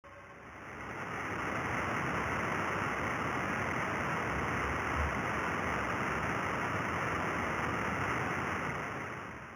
Low Frequency Burst
70Hz_Line.mp3
During the course of the first observing run (O1), the LIGO Livingston detector was plagued by a non-linear effect that caused such bursts of noise at low frequency.